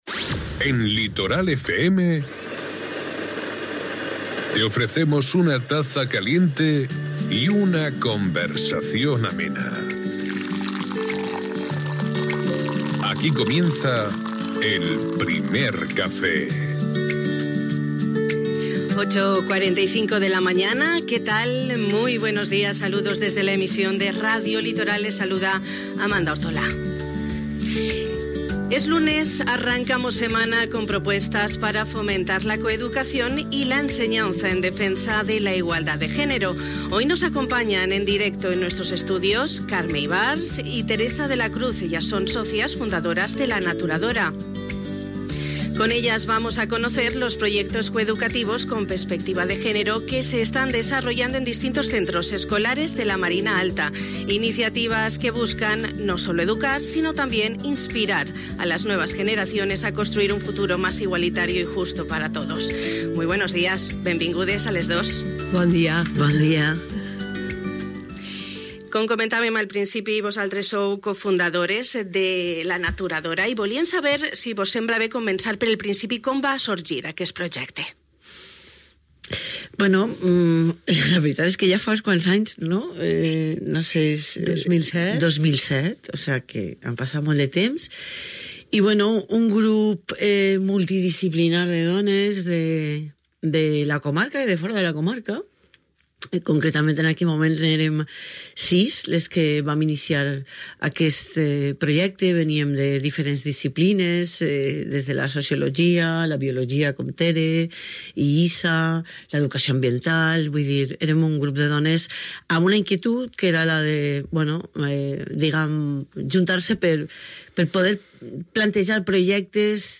En el Primer Café de Radio Litoral hemos arrancado semana con propuestas para fomentar la coeducación y la enseñanza en defensa de la igualdad de género.